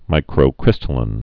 (mīkrō-krĭstə-lĭn)